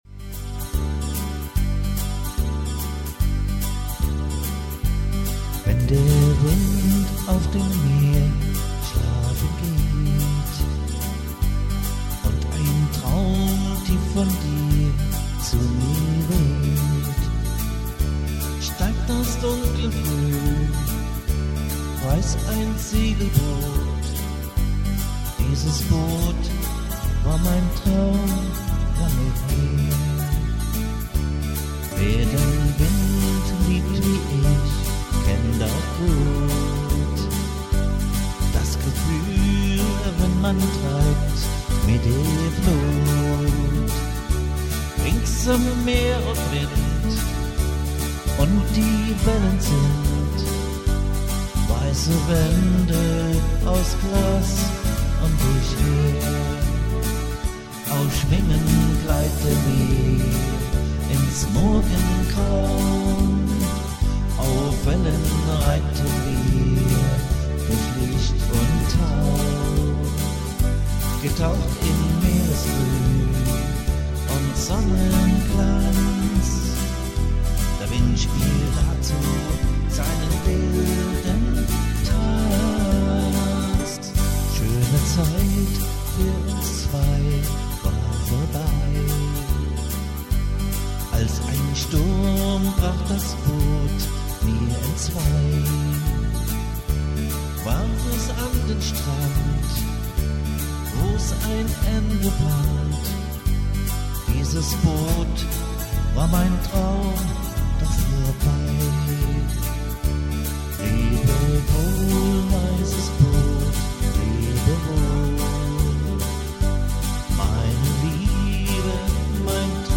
- Livemusik mit Gesang
• Alleinunterhalter